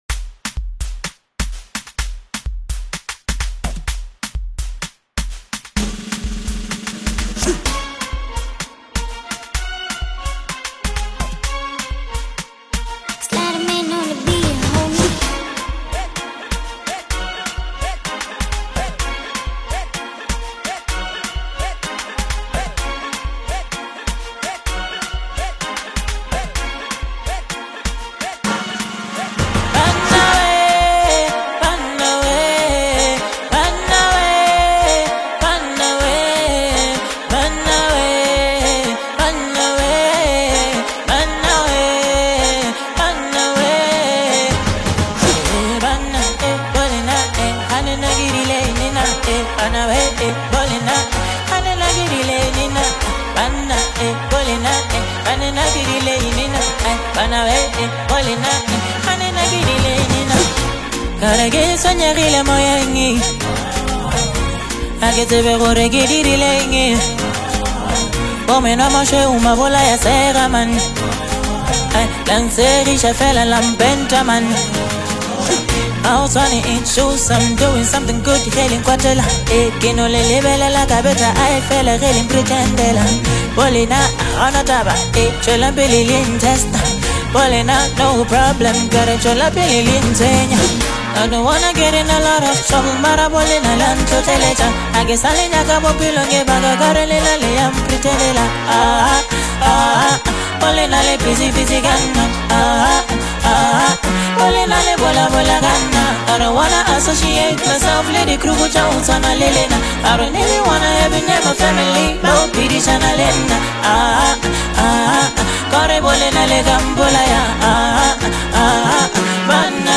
AFRO HOUSE